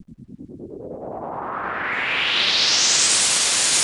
Index of /90_sSampleCDs/Classic_Chicago_House/FX Loops